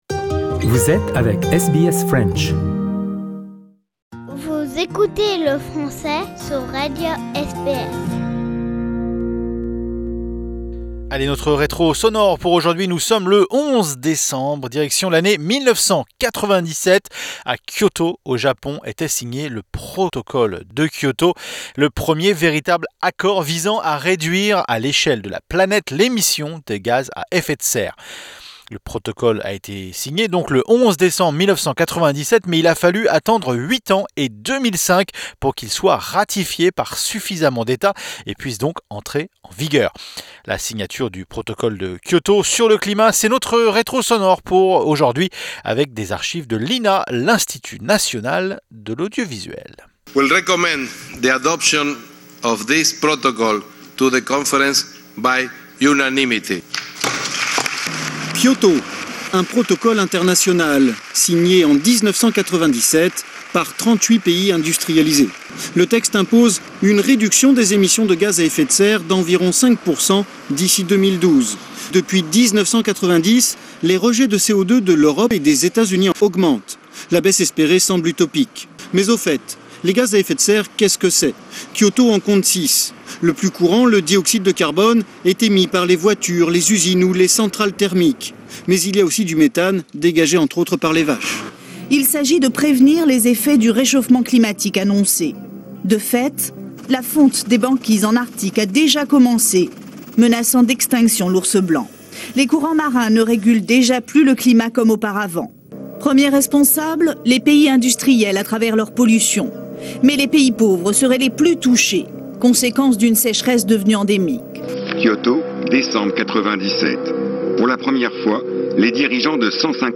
C'est notre rétro sonore avec des archives de l'INA..